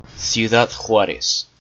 Ciudad Juárez (US: /sjuːˌdɑːd ˈhwɑːrɛz/ sew-DAHD HWAR-ez, Spanish: [sjuˈðað ˈxwaɾes]